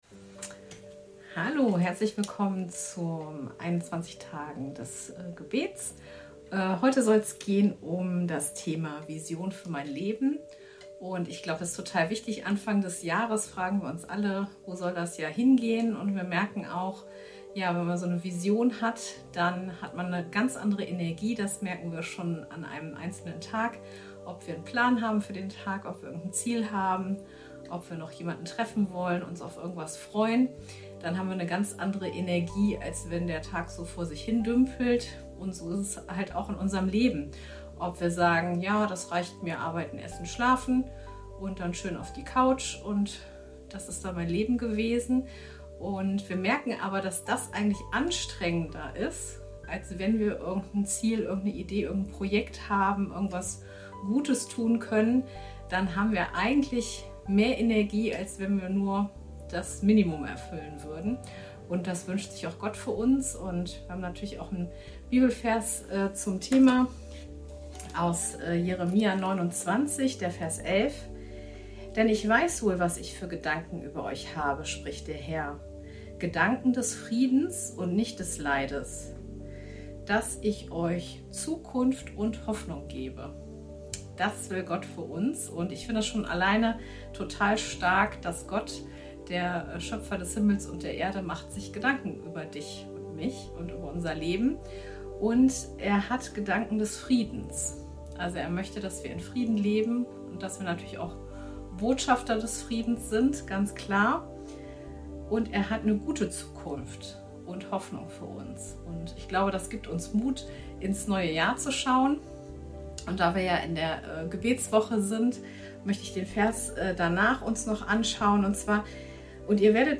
Tag 5 der Andacht zu unseren 21 Tagen Fasten & Gebet